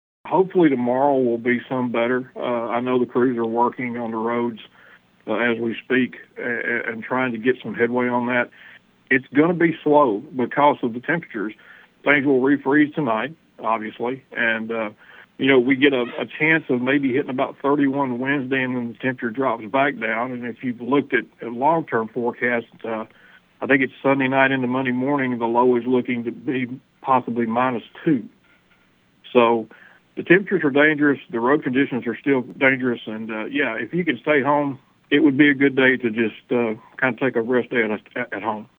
The EMA Director said it was hoped that each day will bring progress to clearing local roadways for normal transportation.(AUDIO)